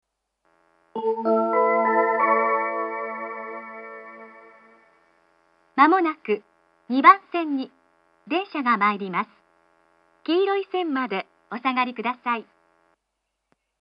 接近放送があり、「東海道型」の放送が使用されています。
２番線接近放送 女声の放送です。